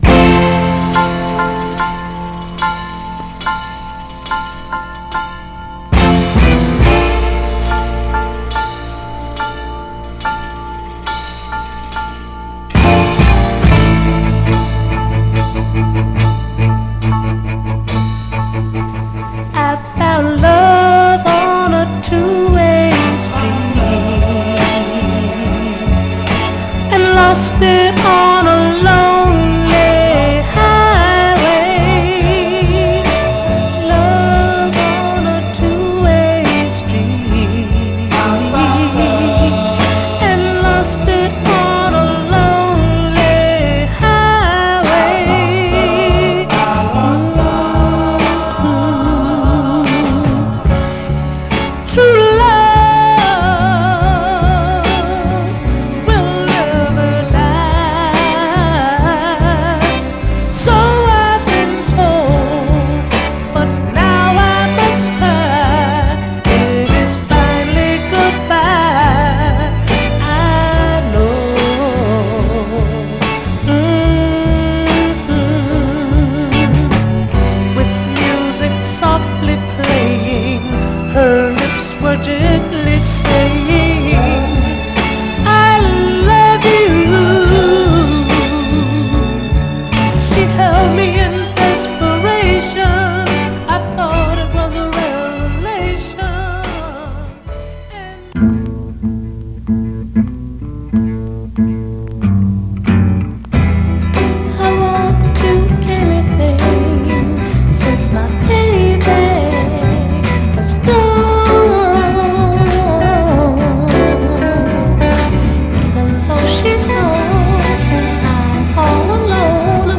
スウィート・ソウルの名ダブルサイダーです。
盤はいくつか細かいヘアーラインキズ、薄い線キズ箇所ありますが、音への影響は少なくプレイ良好です。
※試聴音源は実際にお送りする商品から録音したものです※